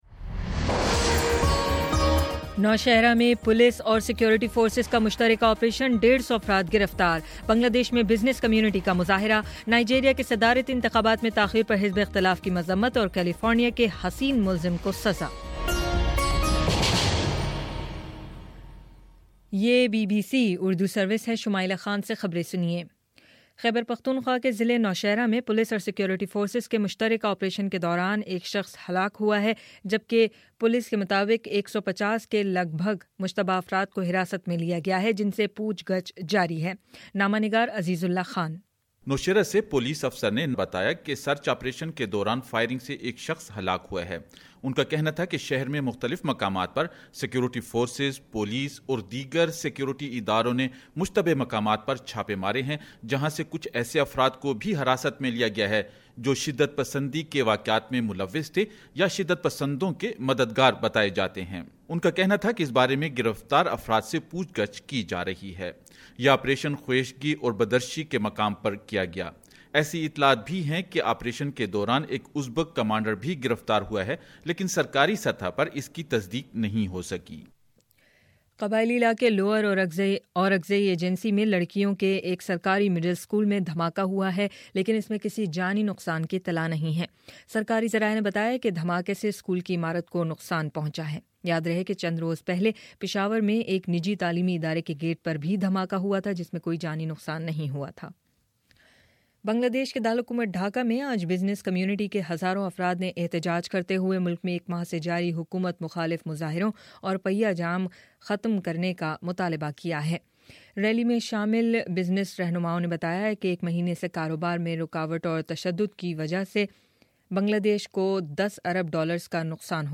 فروری 08: شام چھ بجے کا نیوز بُلیٹن